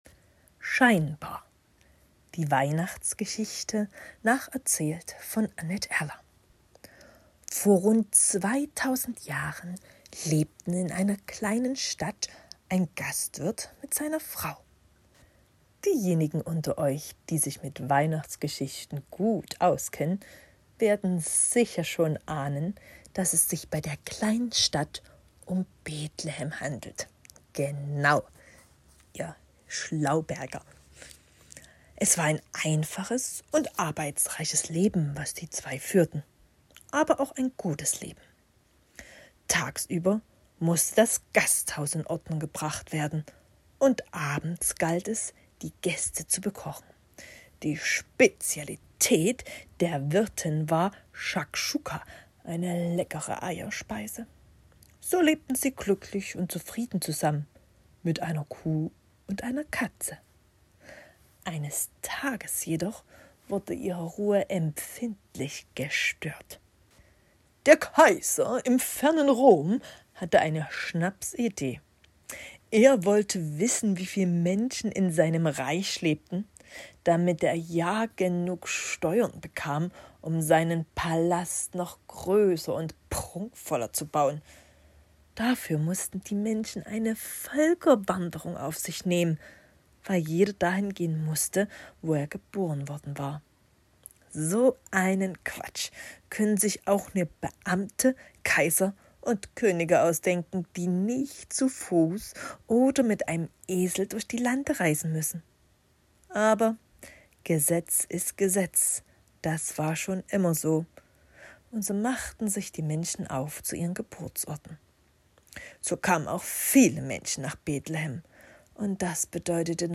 Hörbuchgeschichten